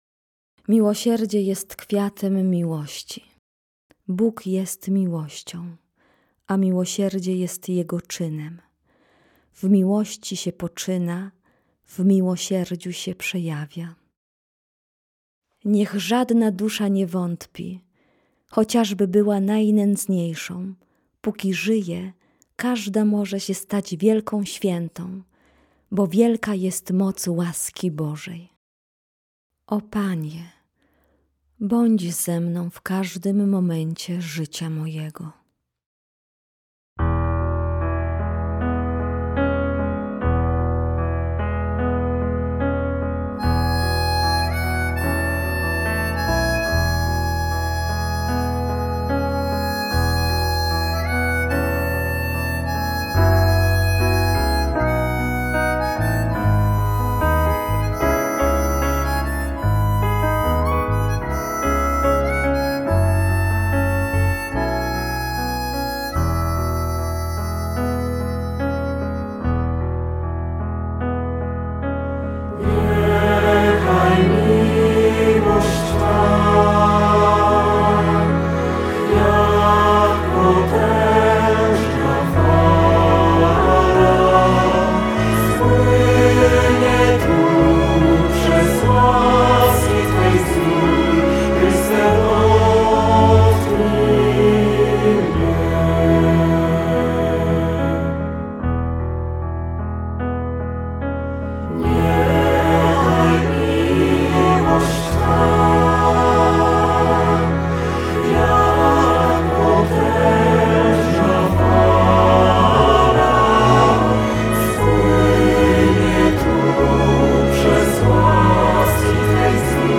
Wywiad udostępniony dzięki życzliwości Radia Jasna Góra
jasna-gora-wywiad-radio.mp3